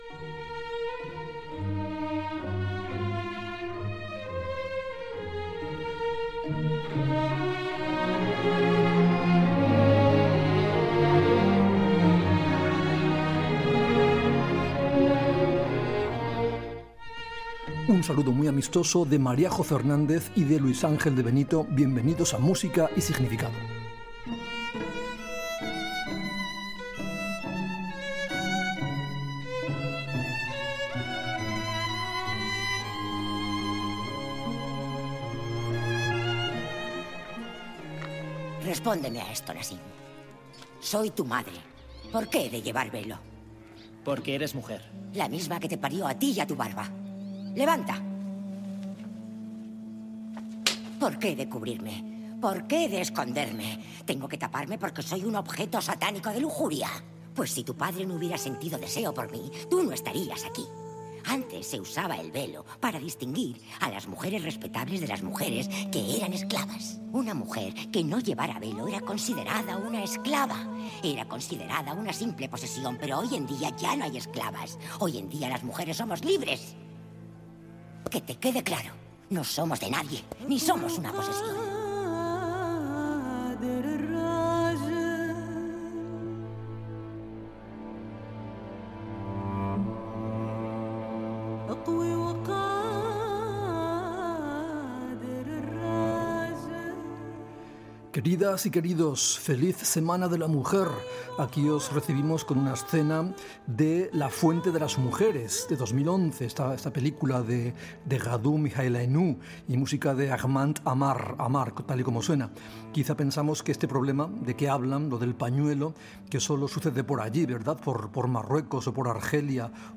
Sintonia, benvinguda, breu dramatització i espai dedicat a les dones compositores
Musical
FM